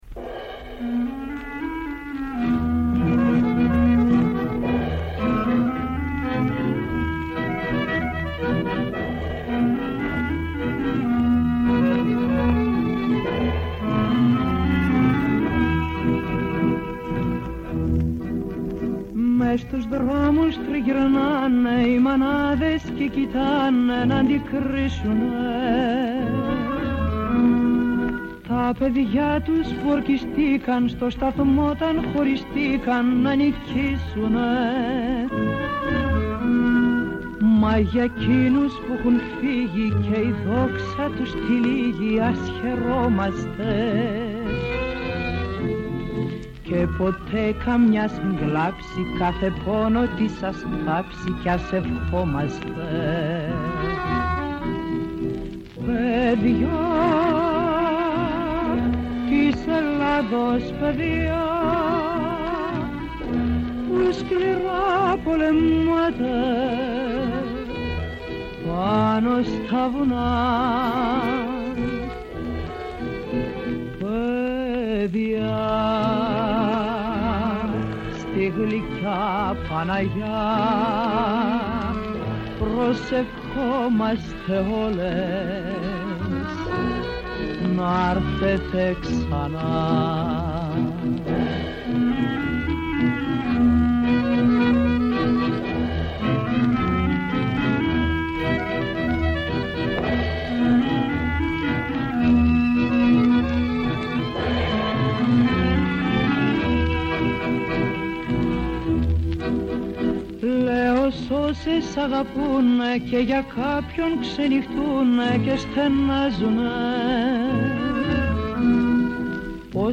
προσεγγίζει τα μεγάλα θέματα της πρώτης γραμμής με καλεσμένους, ρεπορτάζ και σχόλια. ΠΡΩΤΟ ΠΡΟΓΡΑΜΜΑ